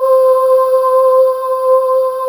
Index of /90_sSampleCDs/USB Soundscan vol.28 - Choir Acoustic & Synth [AKAI] 1CD/Partition C/05-ANGEAILES